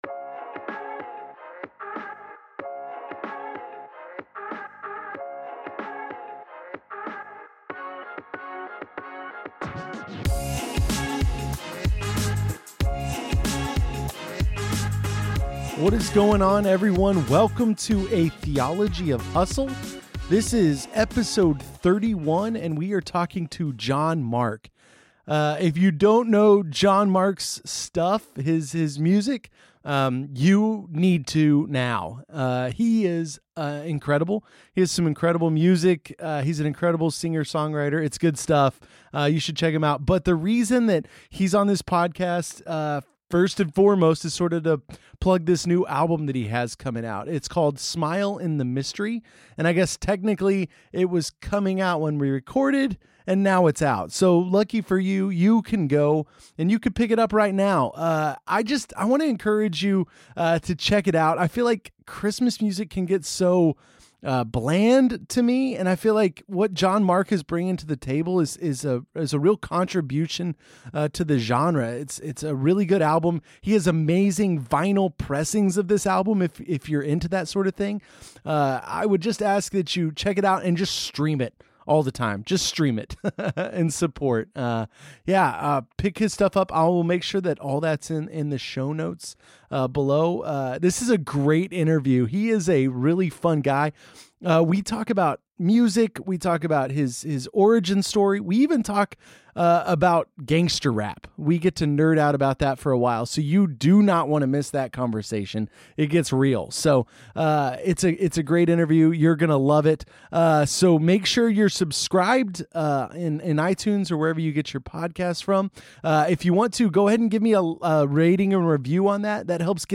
John-Mark-McMillan-Interview.mp3